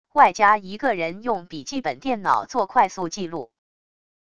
外加一个人用笔记本电脑做快速记录wav音频